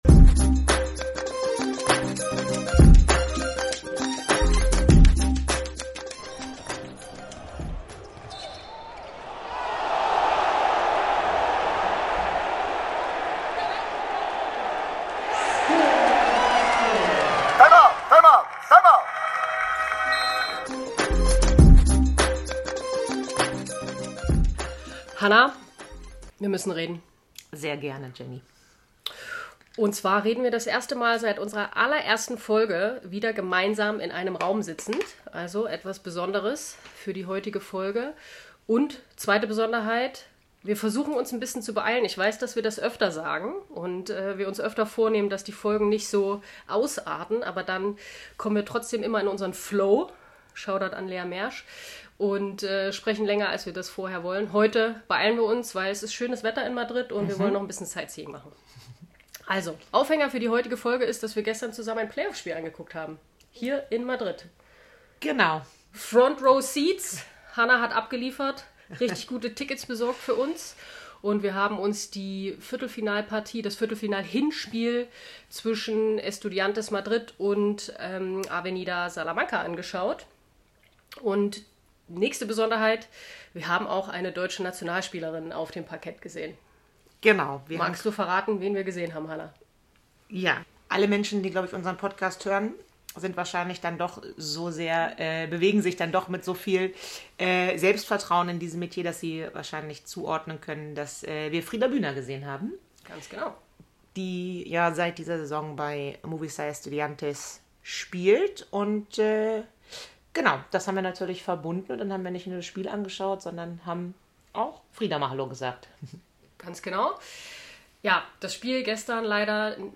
Ein kleiner Abstecher nach Madrid bietet uns die Möglichkeit gemeinsam im gleichen Raum sitzend aufzunehmen - das erst zweite Mal in unserer 21-Folgen-langen Geschichte.